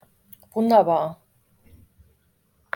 wonderful wunderbar (WUN-der-bar)